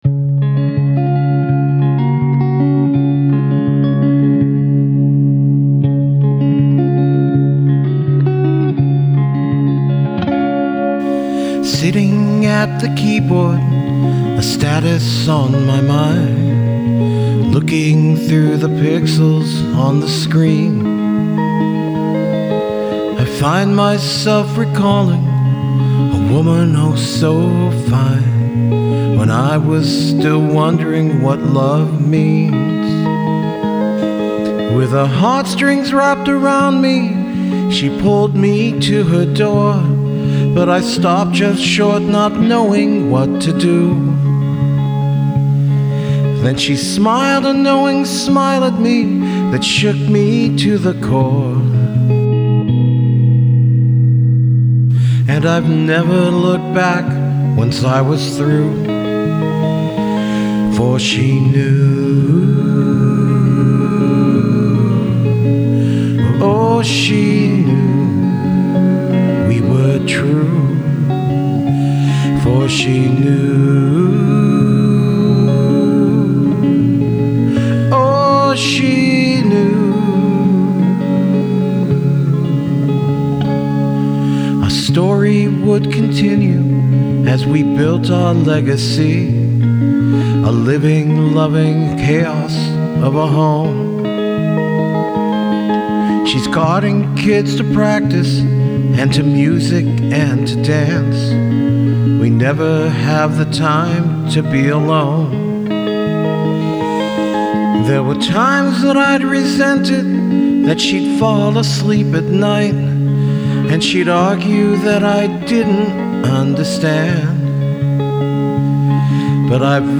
This is a simply song with me singing over a single guitar (my Squier CV Tele). I guess you could call it a love song, but it’s more of a song of understanding where my wife is coming from – at least I think it’s where she’s coming from.